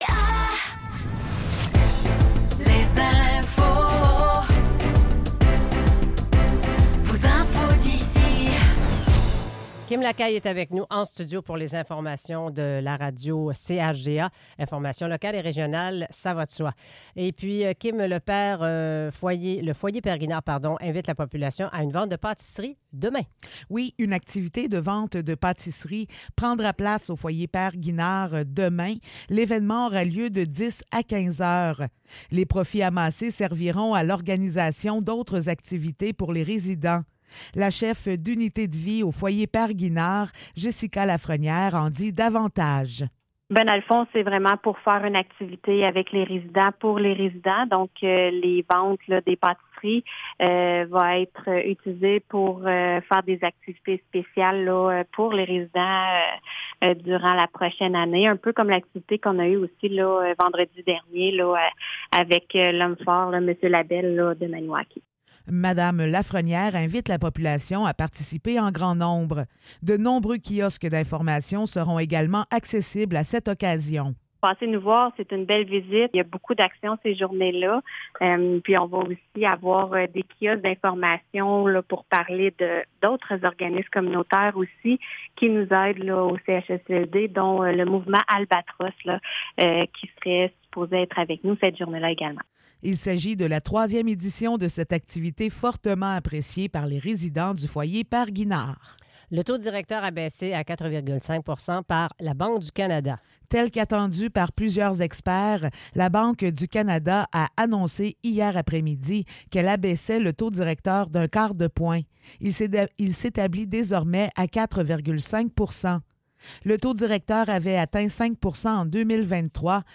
Nouvelles locales - 25 juillet 2024 - 7 h